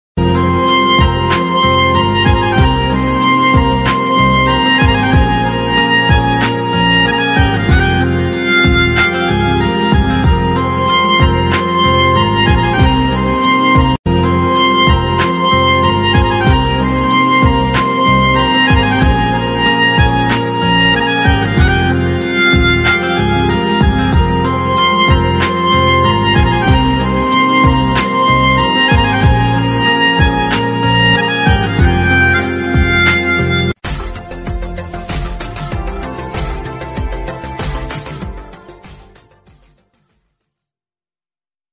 Un tono con una melodía muy relajante.